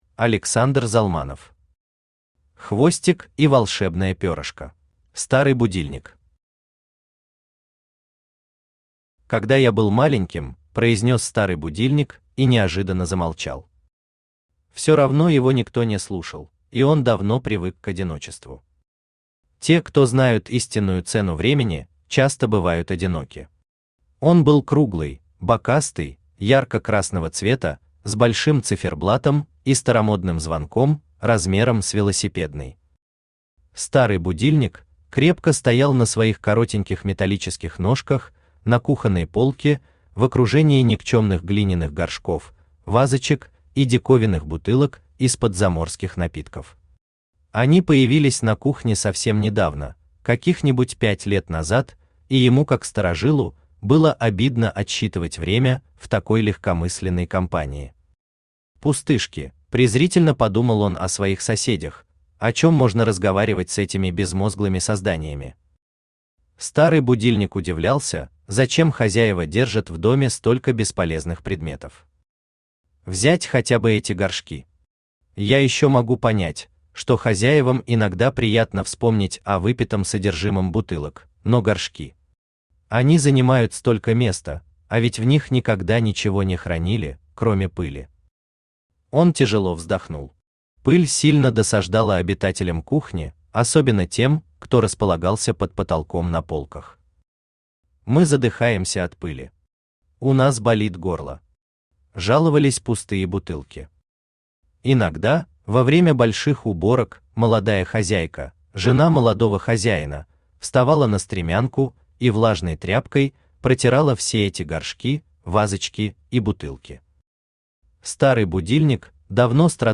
Аудиокнига Хвостик и волшебное перышко | Библиотека аудиокниг
Aудиокнига Хвостик и волшебное перышко Автор Александр Залманов Читает аудиокнигу Авточтец ЛитРес.